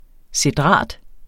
Udtale [ seˈdʁɑˀd ]